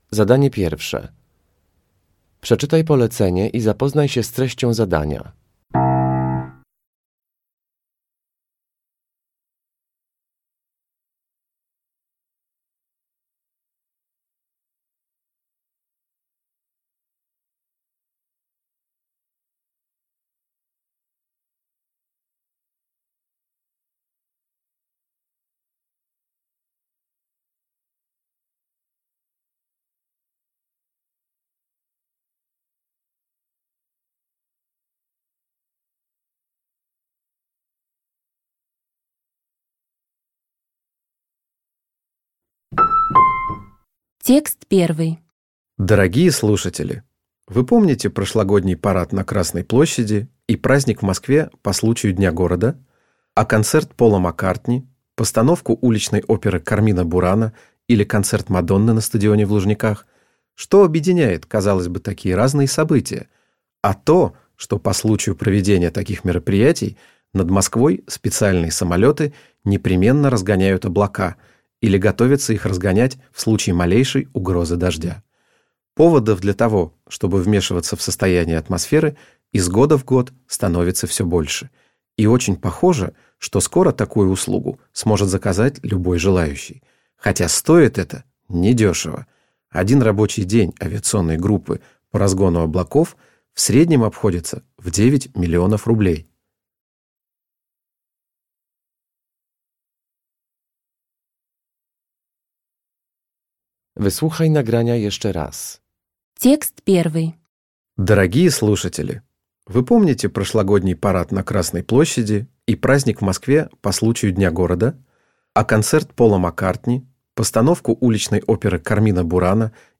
Uruchamiając odtwarzacz z oryginalnym nagraniem CKE usłyszysz dwukrotnie trzy teksty.